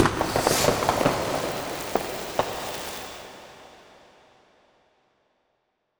fireworks.wav